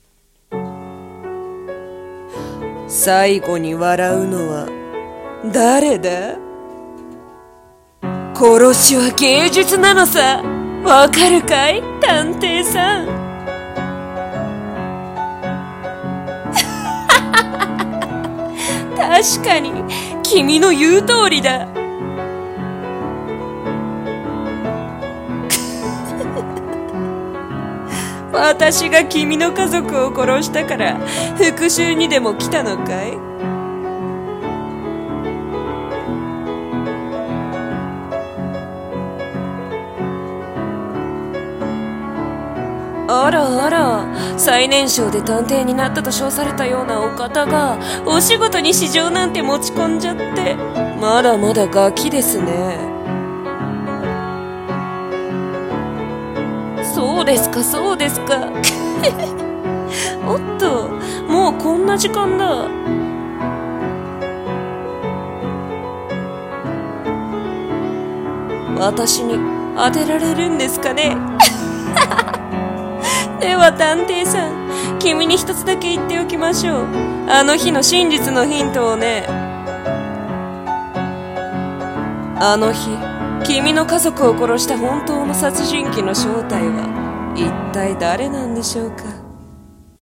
『幕開け』【声劇台本】